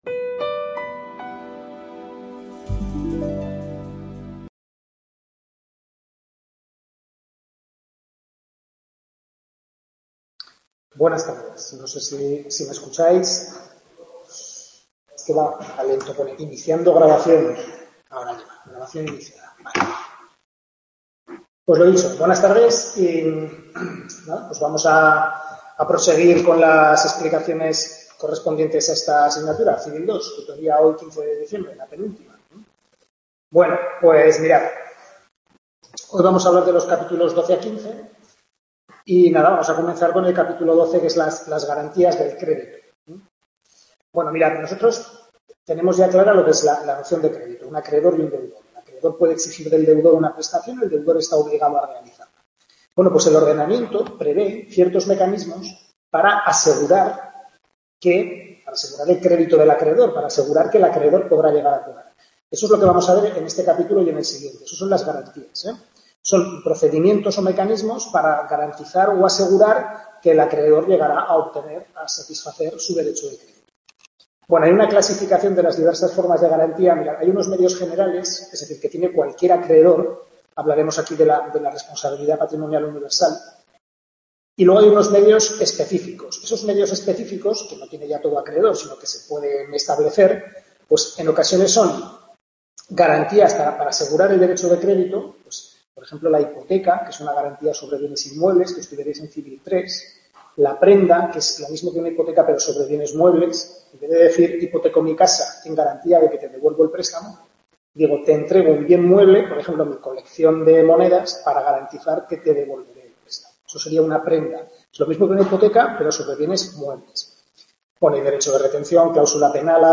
Tutoría de Civil II capítulos 12 a 15 del Manual de Carlos Lasarte